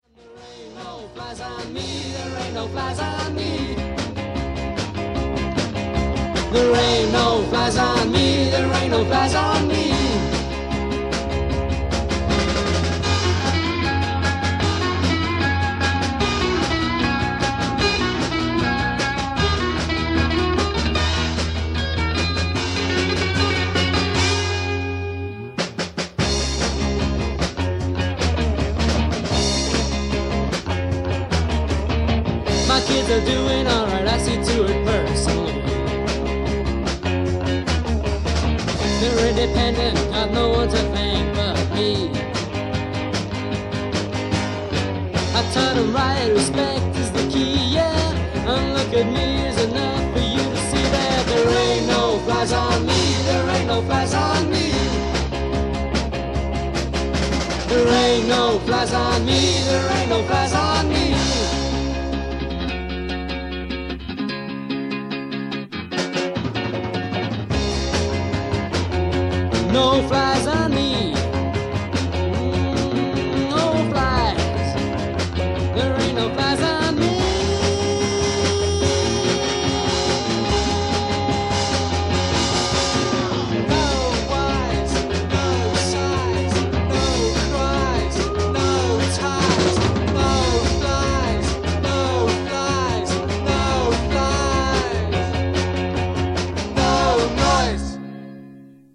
Guitar, vocals
Rhythm guitar, vocals
Drums, vocals